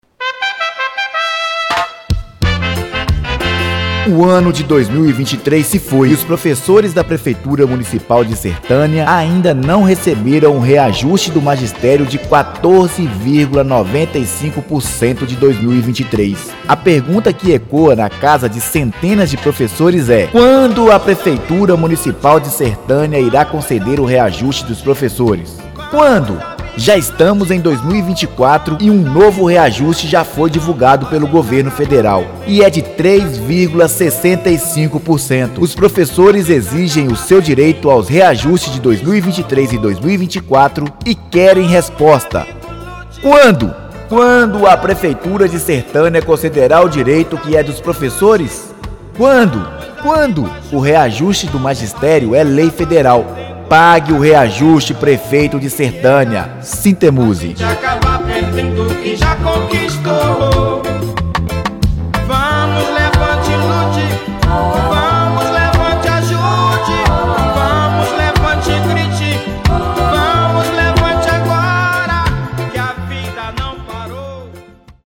Veja a nota distribuída e veiculada em carro de som pelos membros do SINTEMUSE: